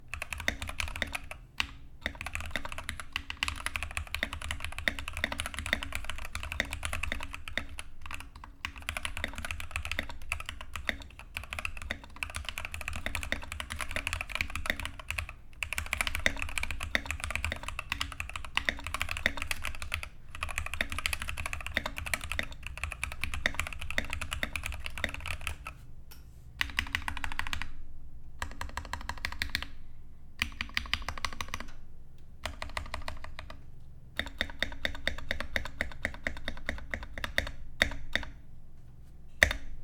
Stuknięcie jest solidne i żywe, a jego głębokość jest dodatkowo uwydatniona przez wysokie, puste w środku, keycapy. Można więc spokojnie nazwać brzmienie Keychron V4 basowym.
Choć przełączniki nie zostały nasmarowane w żadnym stopniu, to ich sprężynki nie rezonująna na tyle głośno, aby były słyszalne podczas pisania.
Jeśli natomiast chodzi o głośność, Keychron V4 zdecydowanie nie należy do grupy najcichszych klawiatur.
Tak brzmi klawiatura Keychron V4
recenzja-Keychron-V4-soundtest.mp3